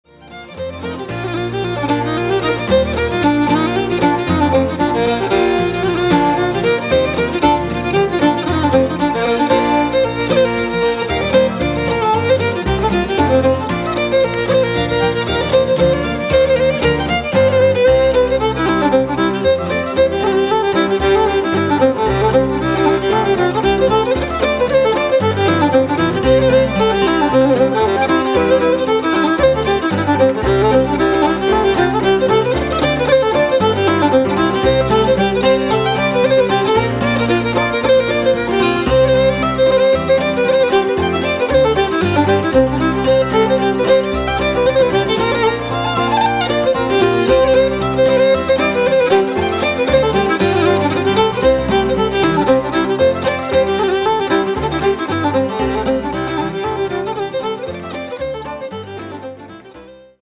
(reels)